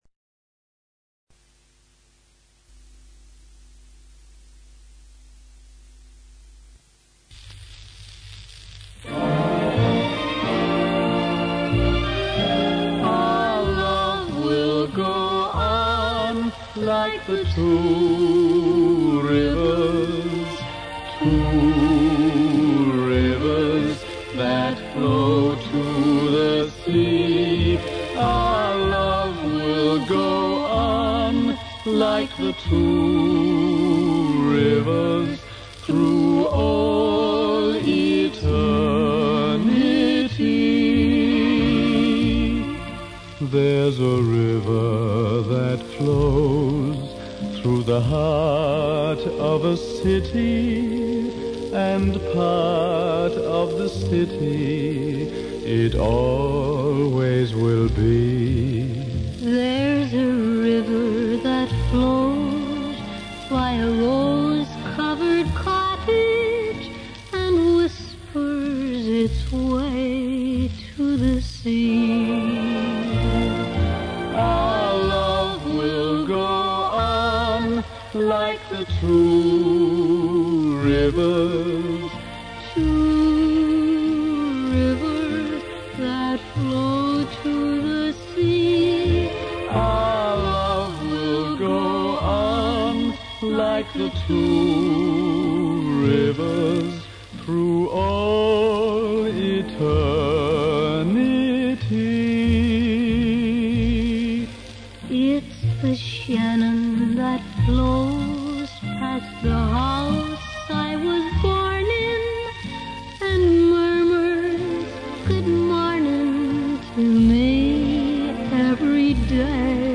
from a 78 rpm shellac record released in 1956